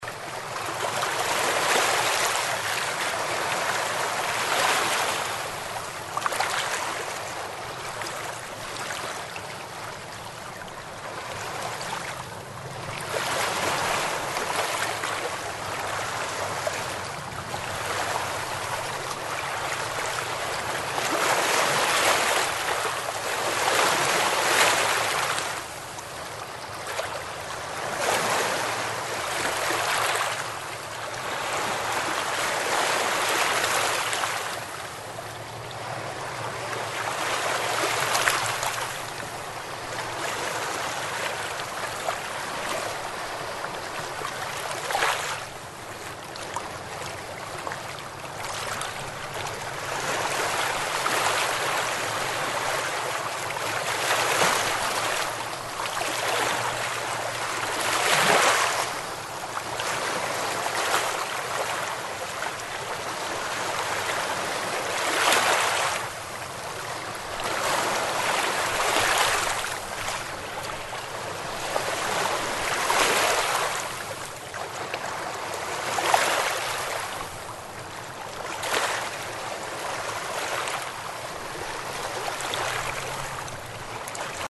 Звуки реки
На этой странице собраны натуральные звуки рек и ручьев в высоком качестве.